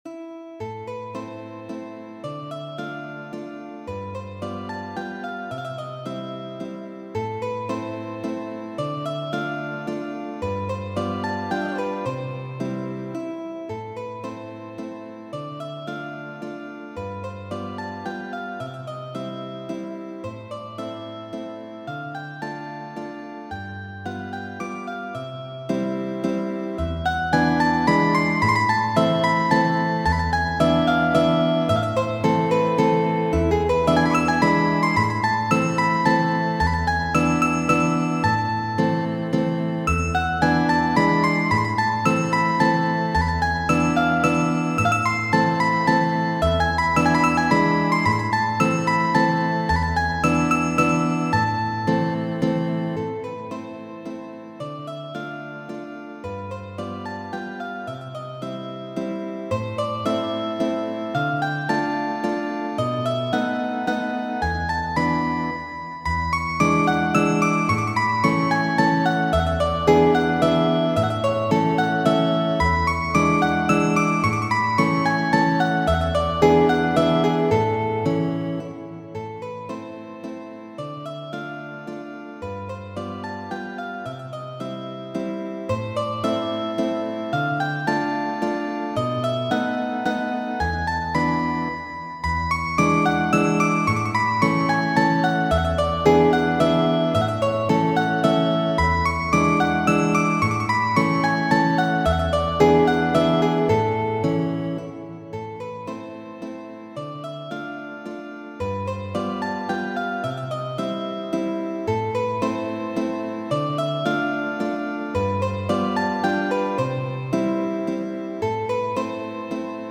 Valso lenta, verko 18-2 de Federiko Ĉopin'.
valsolenta.mp3